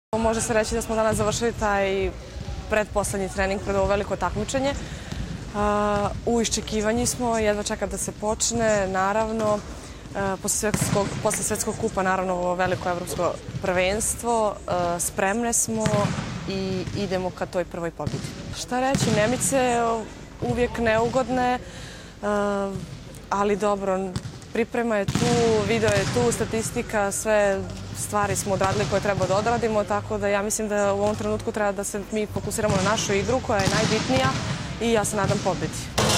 IZJAVA SILVIJE POPOVIĆ